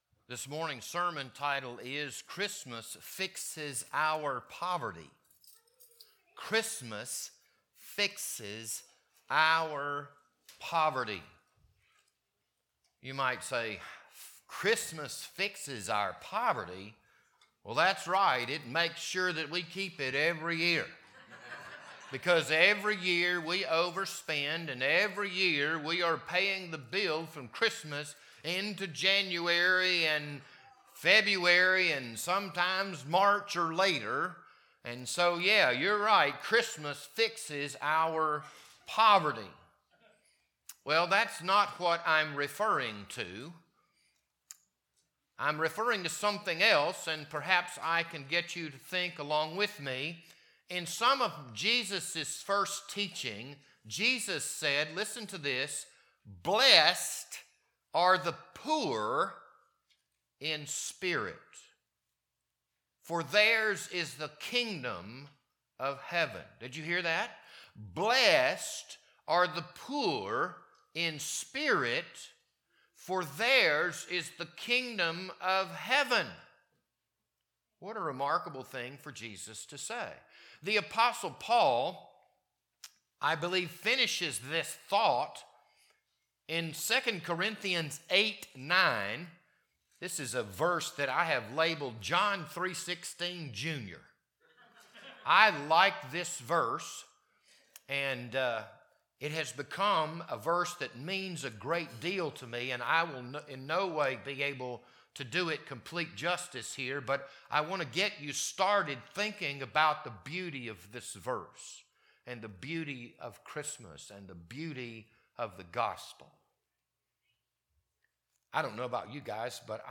This Sunday morning sermon was recorded on December 21st, 2025.